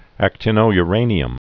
(ăk-tĭnō-y-rānē-əm, ăktə-nō-)